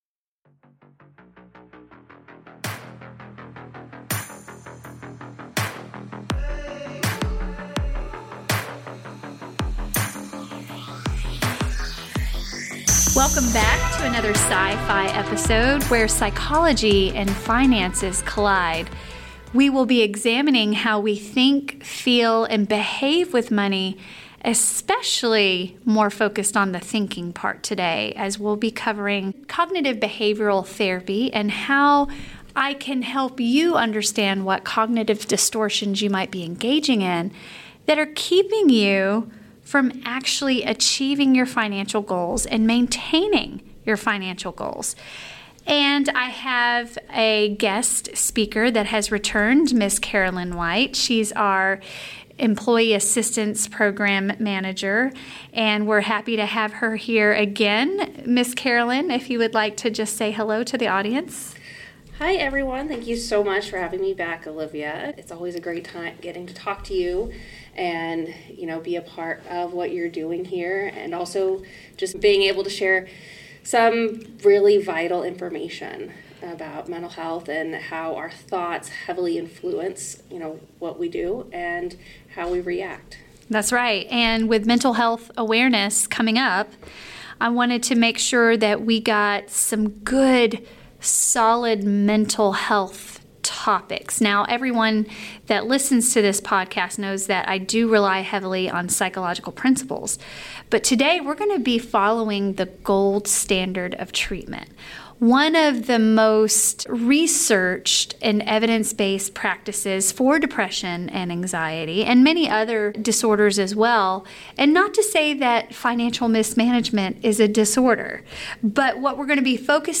In part 1 of this 3-part series, 2 therapists help you examine how you THINK with money, which impacts how you feel and ultimately behave with money. The top 10 cognitive distortions that plague our everyday thinking are generalized to money.